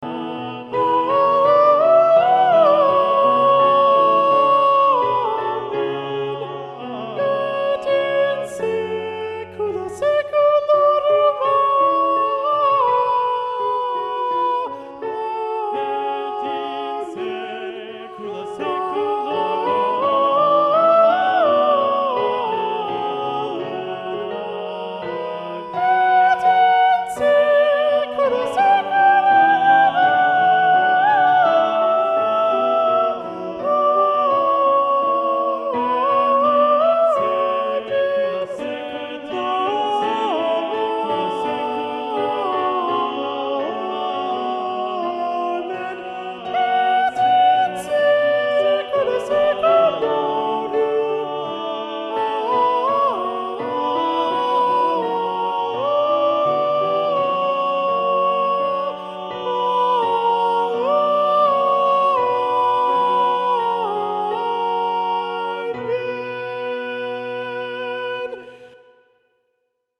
Soprano learning track
domine_soprano.mp3